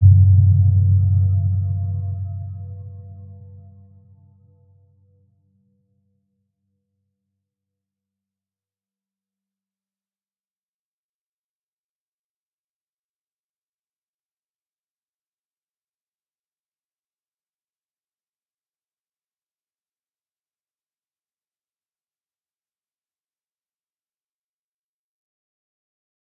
Round-Bell-G2-f.wav